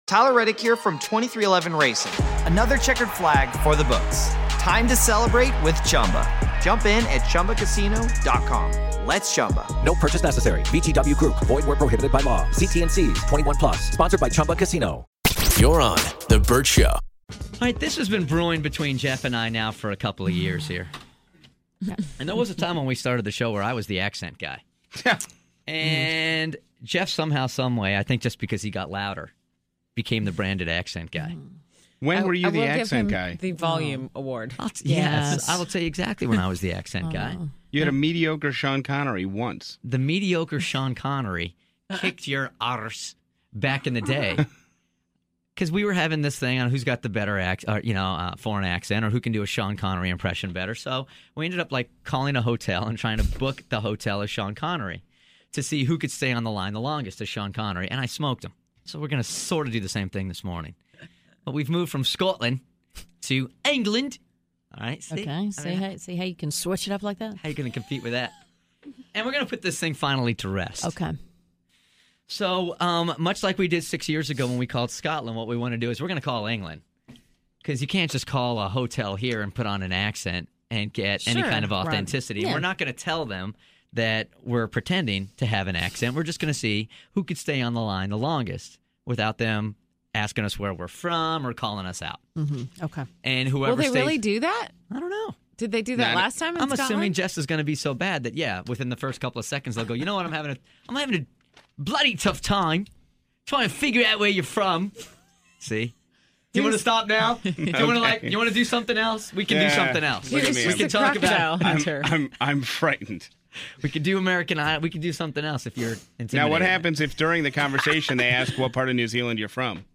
British Accent Dual!